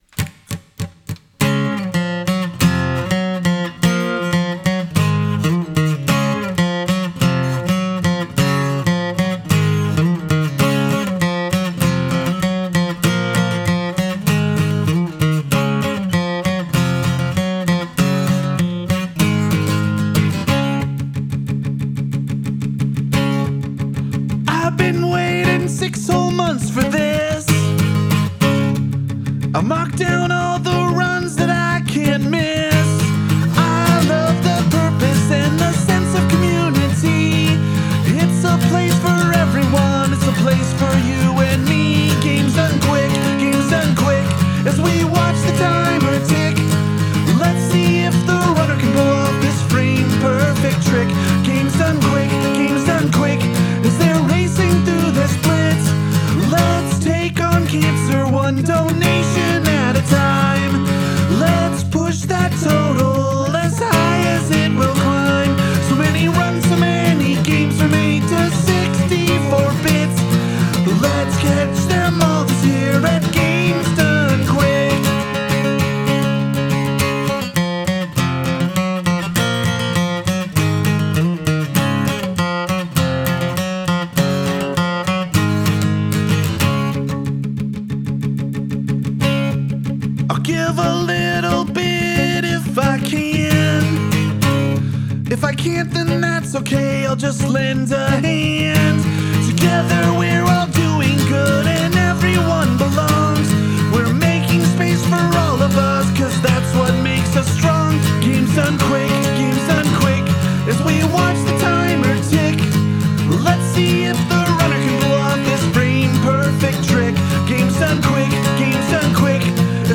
Musically, this is fairly diatonic, although the bridge veers off into the territory of the key’s parallel minor (Db major to C# minor).
The very end also includes the “Mario cadence” (flat-VI to flat-VII to I).
This song is multitracked, with the Zoom recorder capturing the acoustic guitar part and the SM-58 on the vocals.